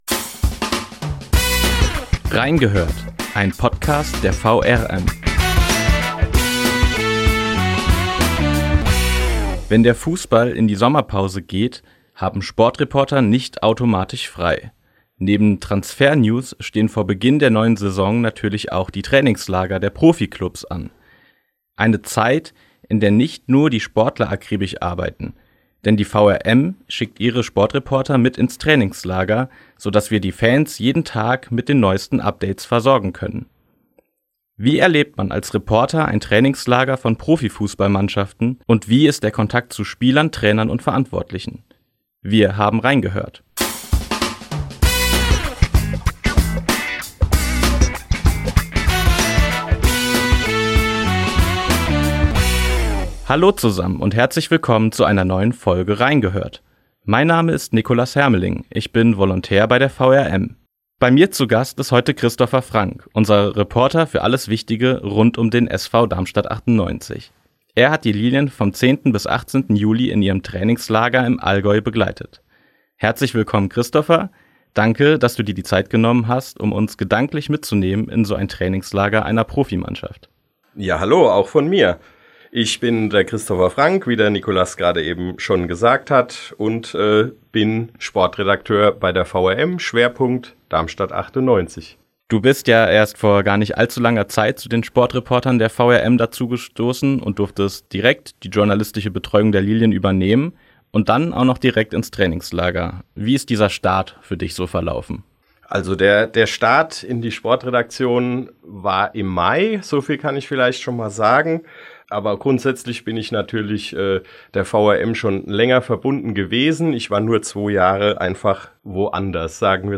Junge Journalisten blicken gemeinsam mit erfahrenen Reportern auf aktuelle Themen und bewegende Geschichten aus Wiesbaden, Mainz und Darmstadt. Gemeinsam erzählen sie, wie die Geschichten entstehen, die sie für den Wiesbadener Kurier, die Allgemeine Zeitung und das Darmstädter Echo recherchieren.